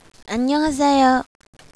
Hello - Bonjour [bohn-zhoor]